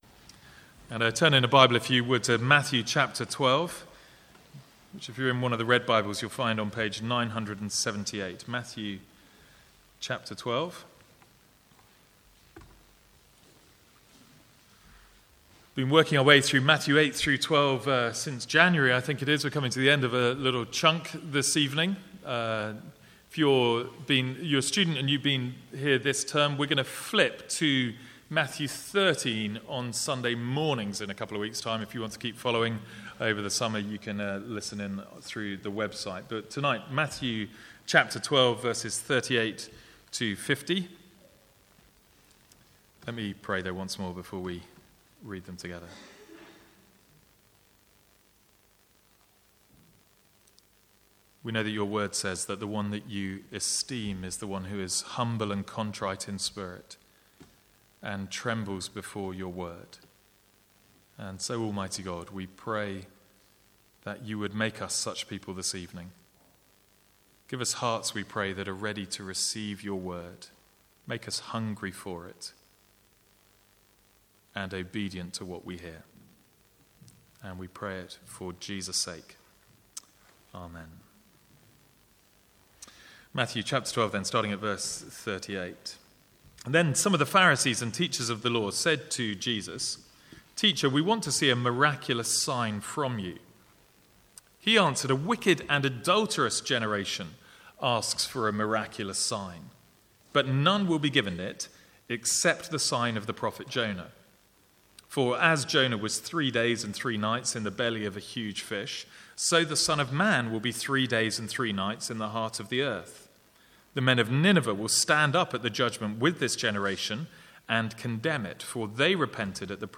Sermons | St Andrews Free Church
From the Sunday evening series is Matthew.